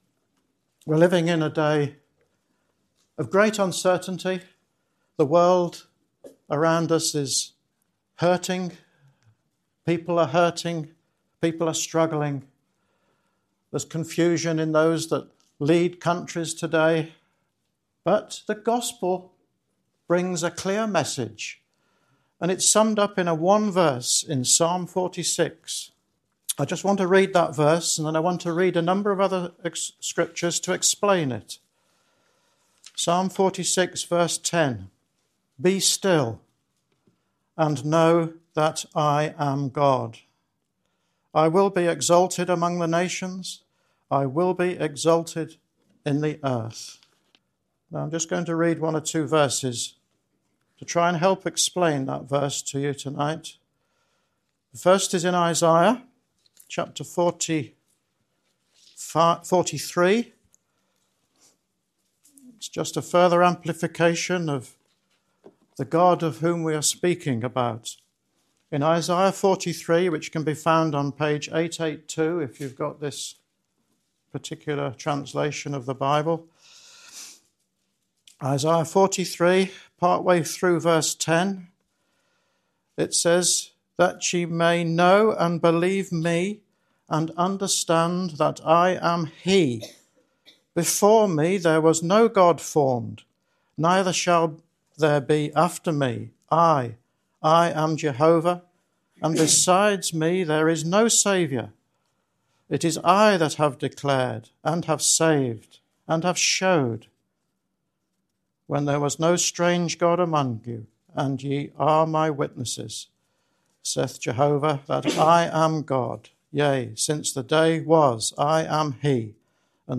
In a world filled with noise, pressure, and constant movement, this preaching invites you to pause and hear the good news. Reading from Psalm 46, it reveals our need for salvation and points to Jesus Christ—who came to save, bring forgiveness, and give new life.